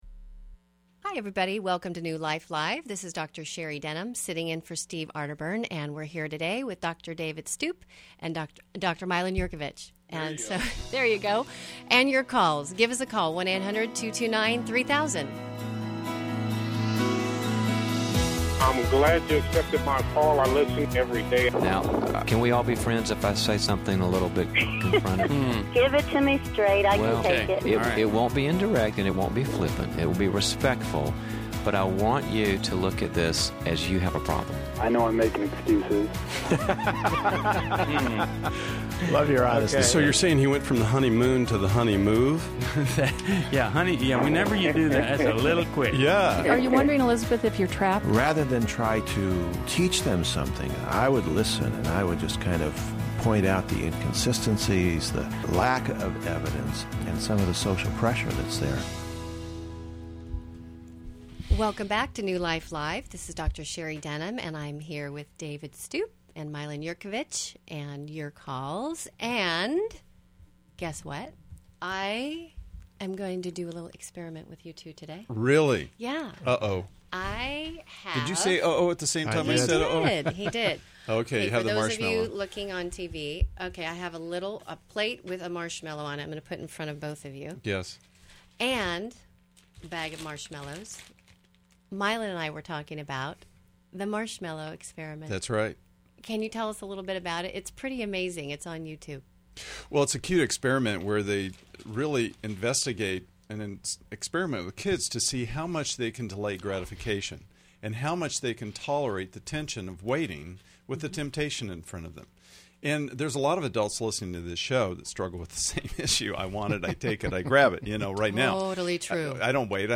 New Life Live: June 1, 2011 addresses tough issues like marital reconciliation, OCD, and sex addiction, with callers seeking guidance on healing and boundaries.
Caller Questions: 1.